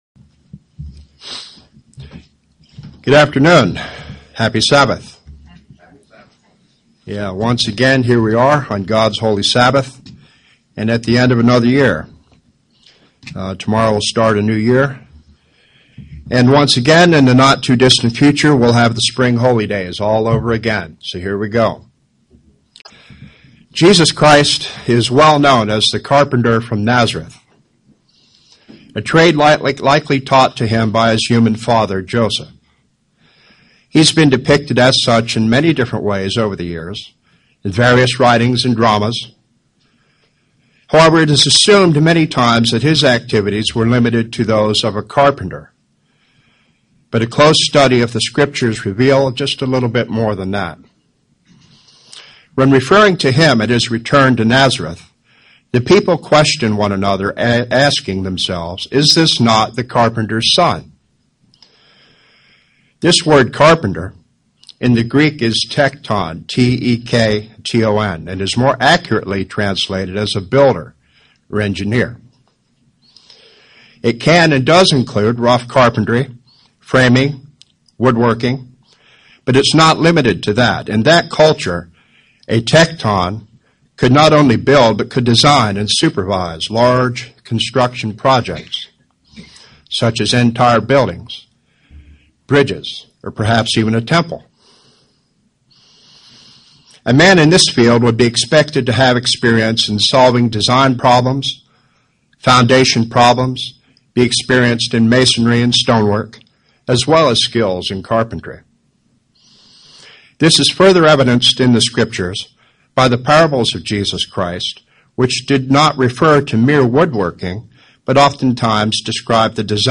Given in Atlanta, GA
UCG Sermon Studying the bible?